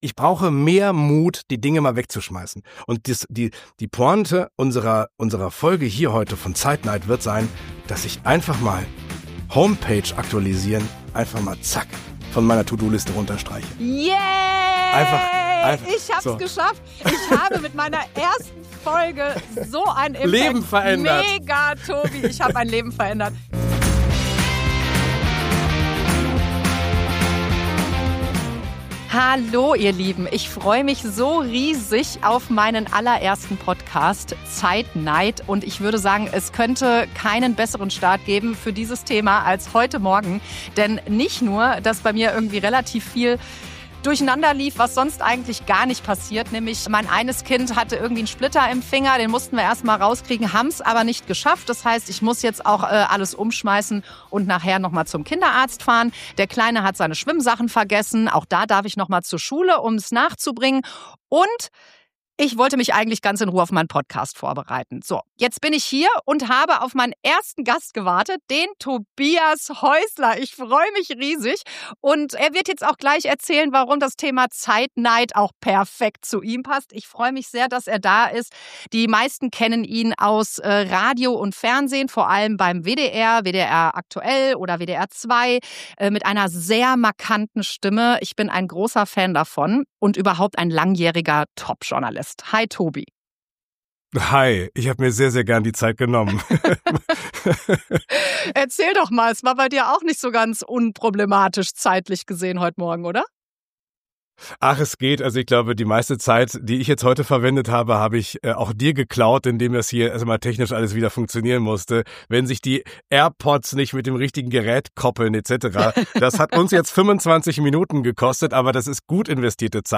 Ein ehrliches Gespräch über Zeitneid, Kalenderchaos und den Versuch, sich selbst nicht immer hintenanzustellen.